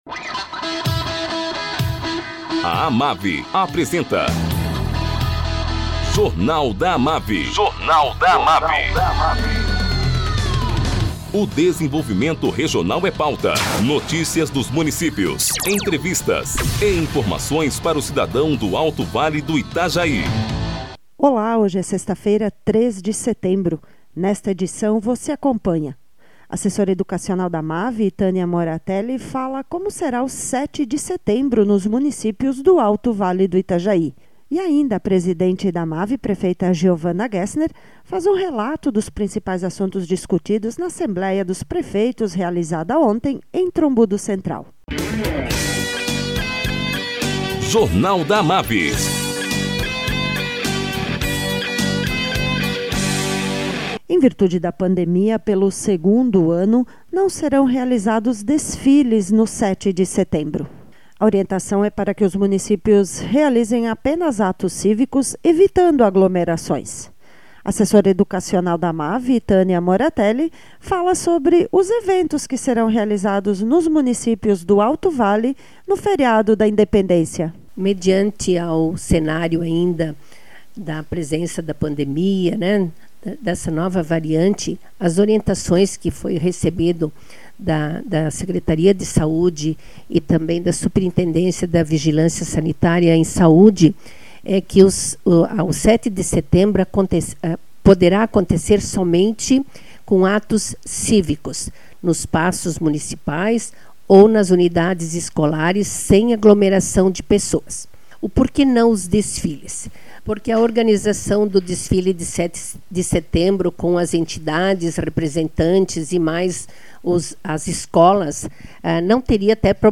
Presidente da AMAVI, prefeita Geovana Gessner, fala sobre as principais deliberações da assembleia realizada ontem.